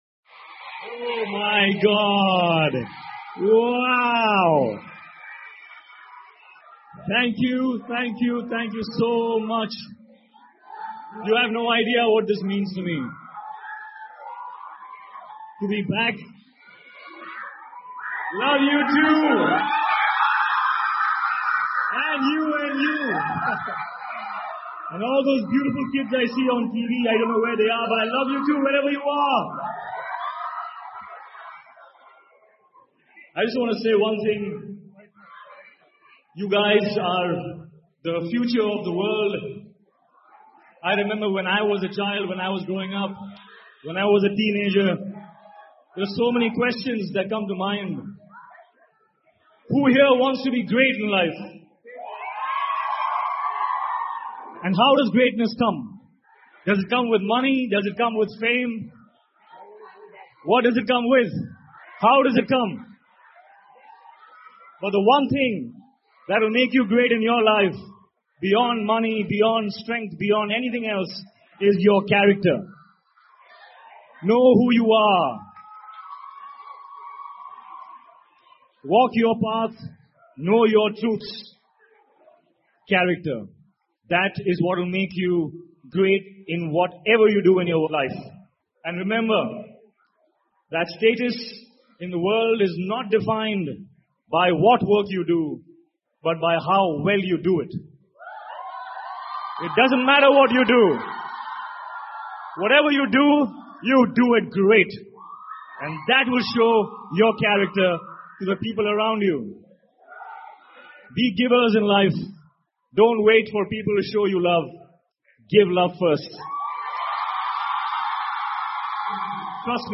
英文演讲录 赫里尼克·罗斯汉：每个人都可以是超人(1) 听力文件下载—在线英语听力室